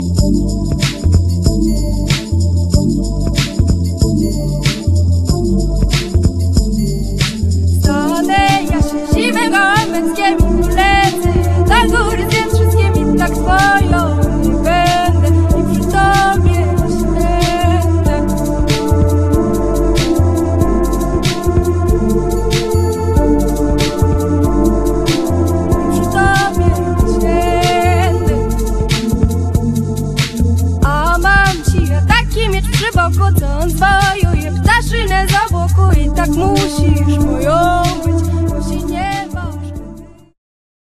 radosne, żywiołowe, roztańczone.